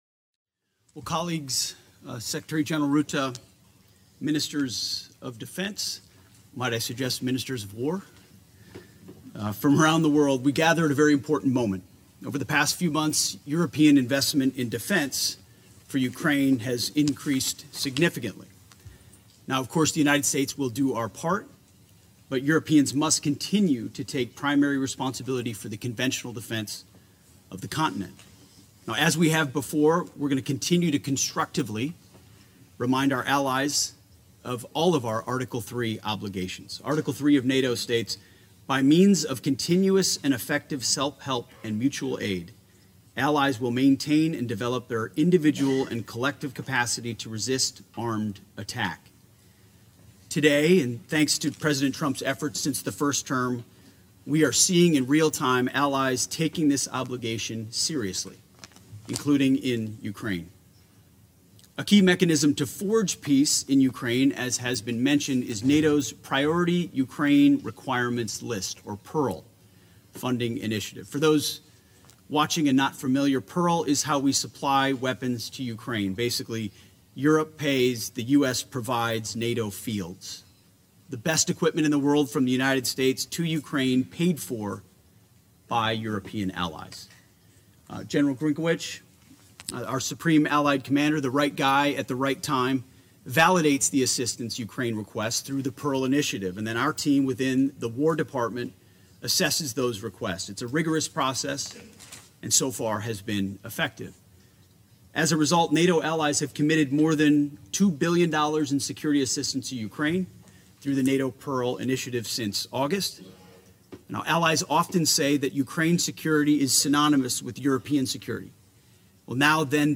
delivered 15 October 2025, NATO HQ, Brussels, Belgium